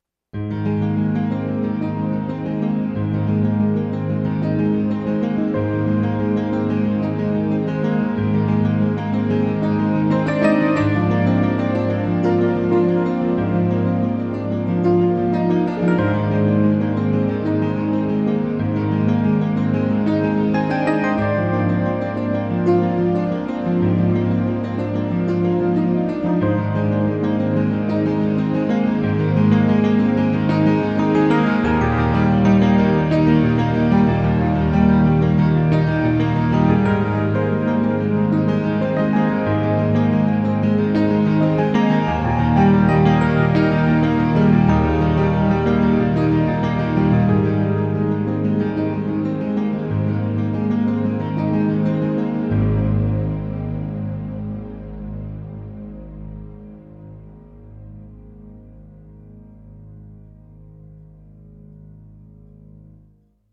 in his makeshift home studio
These five pieces are all piano or keyboard based, and span over 5 years of creative effort.
I changed the background part to a mellow piano with an airy string pad.
Finally I added a nice ending chord after a minute, giving us plenty of time to walk down the aisle.